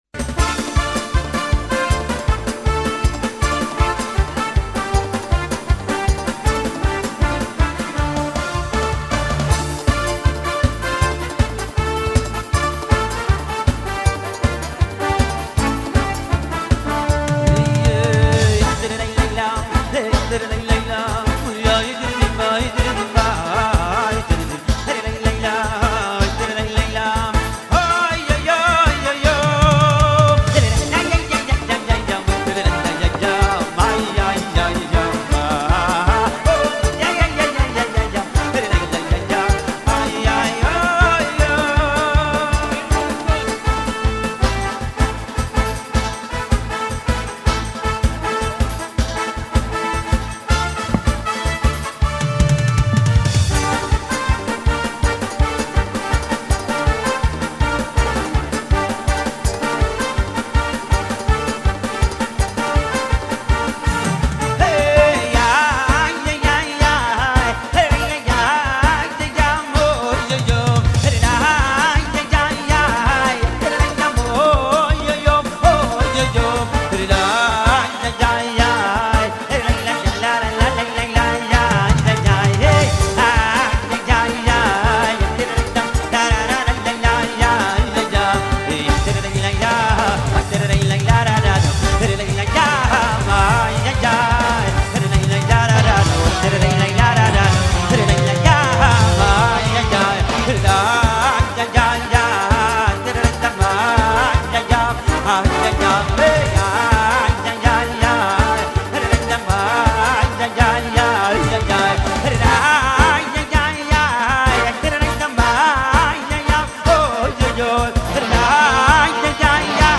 רצועת שמע להרקדה פעילה